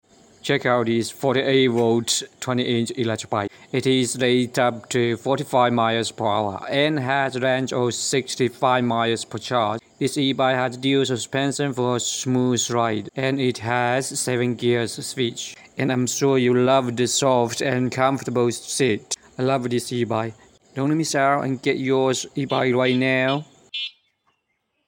e bike sound effects free download